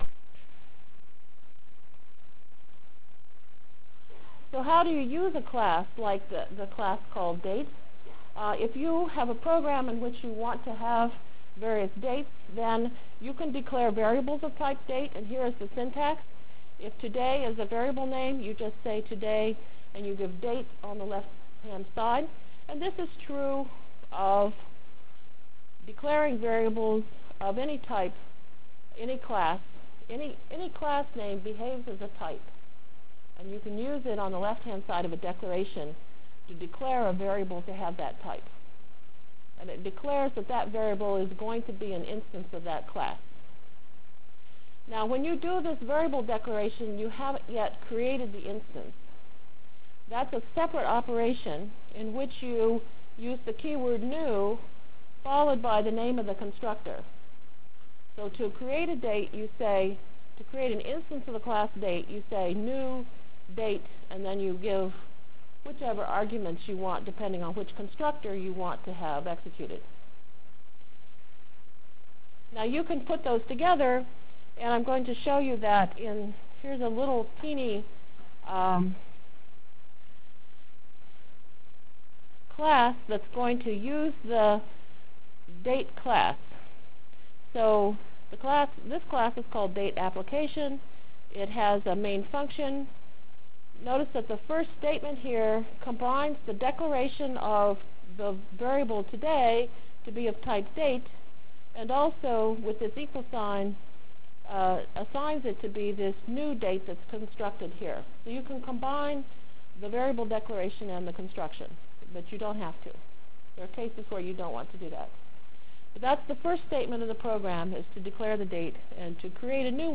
Delivered Lecture for Course CPS616